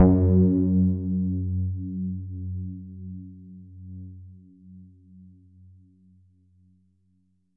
SOUND  F#1.wav